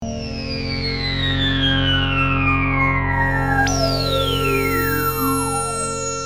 Free MP3 vintage Sequential circuits Pro-600 loops & sound effects 2